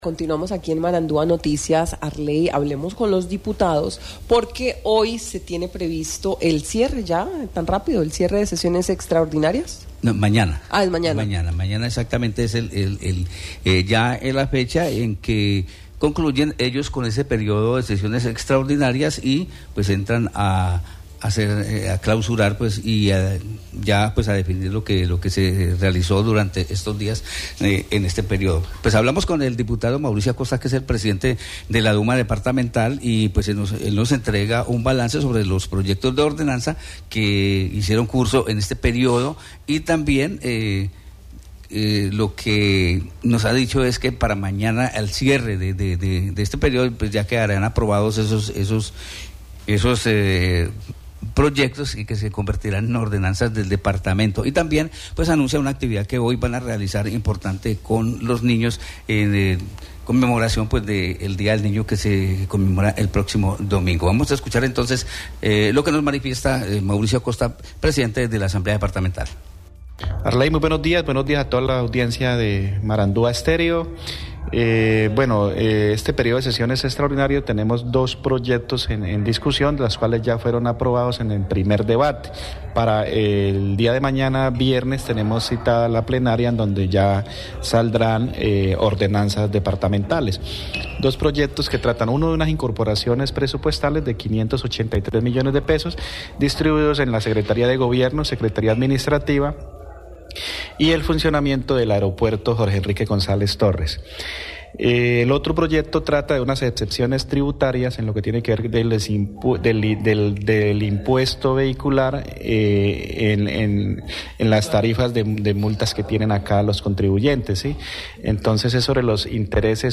El diputado Mauricio Acosta, presidente de la Asamblea del Guaviare, entregó en Marandua Noticias un balance sobre los dos proyectos de ordenanza que hacen su curso y que se votarán mañana al cierre del periodo de las sesiones extraordinarias para convertirse en ordenanzas del departamento.